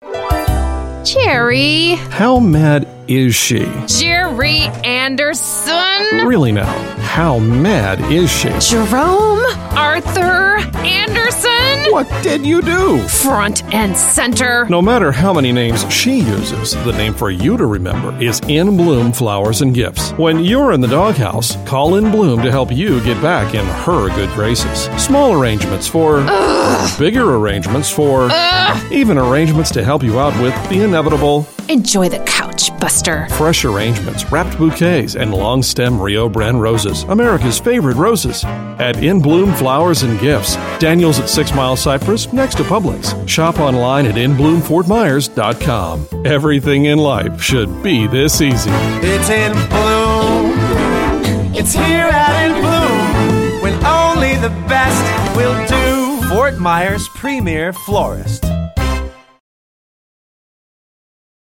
Hot spots fill out tracks 4 through 11, and promos light up tracks 12 through 26.